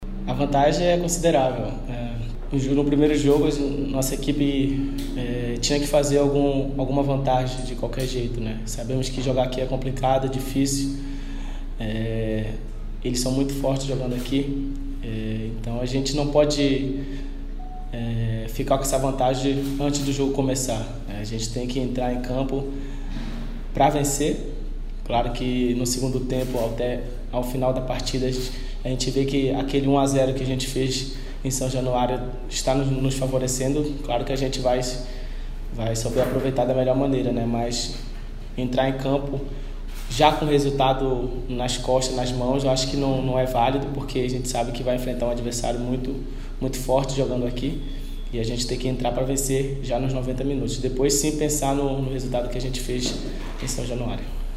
Yago Pikachu, um dos mais experientes jogadores do time entende bem isso e avisou que assim será, principalmente no segundo tempo.